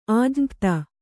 ♪ ājñapta